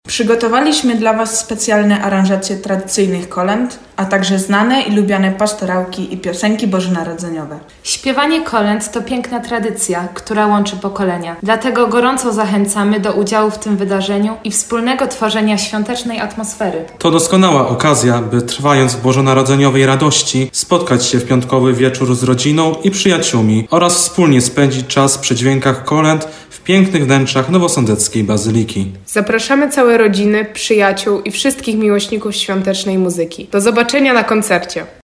– mówią młodzi z zespołu działającego przy sądeckiej bazylice.